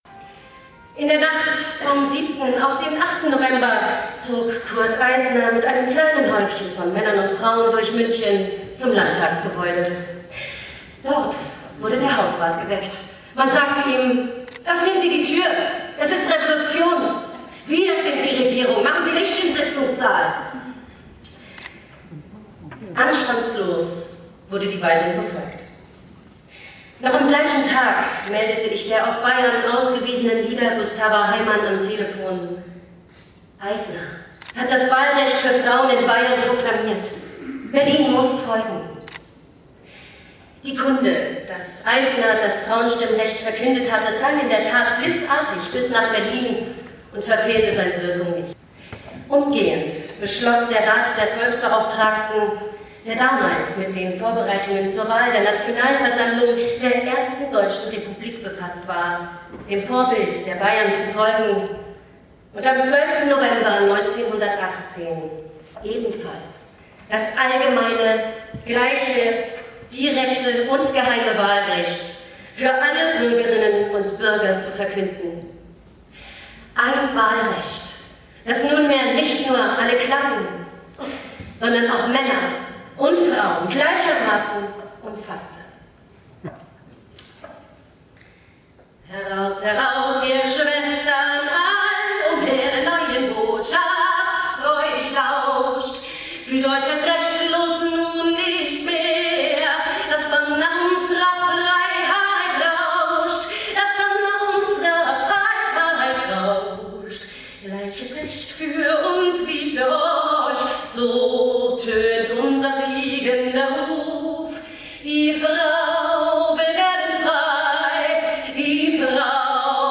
Festveranstaltung “100 Jahre Frauenstimmrecht” 12. November 2018
Und das alles im Plenarsaal von Potsdam im wunderschönen Stadtschloss.